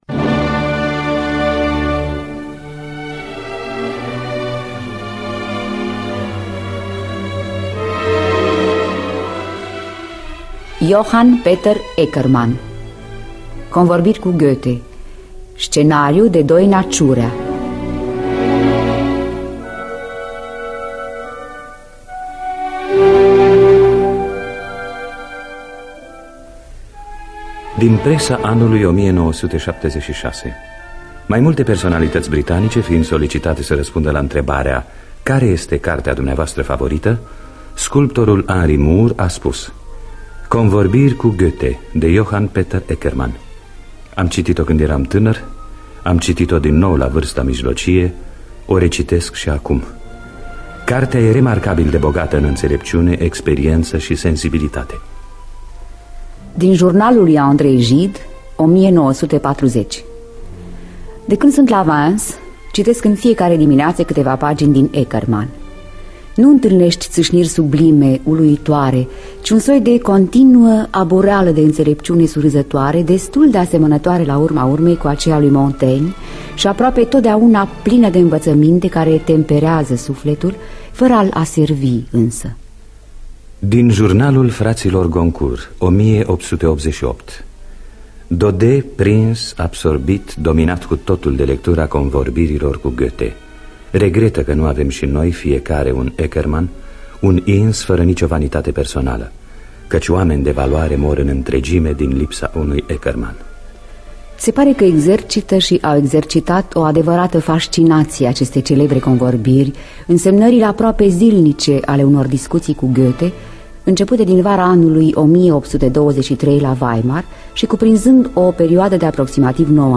Scenariu radiofonic de Doina Ciurea.